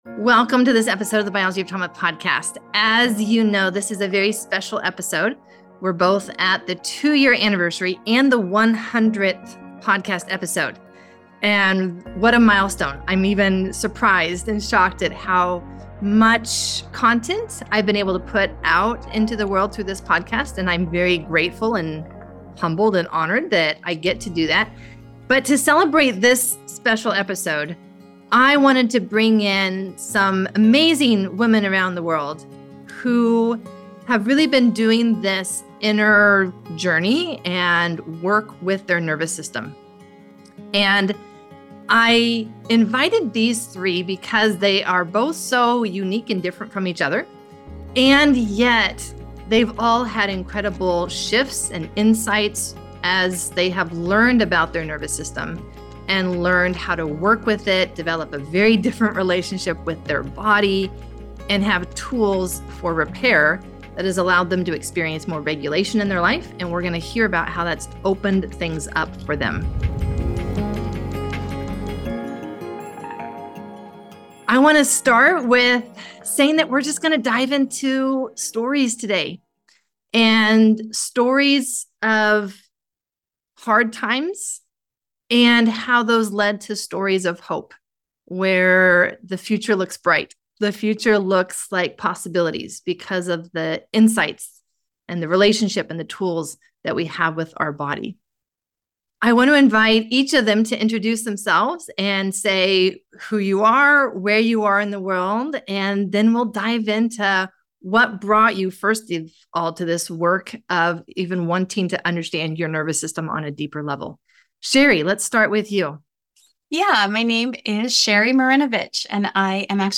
Celebrating 100 episodes and two years of exploring trauma’s biology. In this episode, three women share how understanding their nervous systems changed their lives. Their stories show what’s possible when you work with your biology over time—more regulation, better health, clearer thinking, and greater capacity.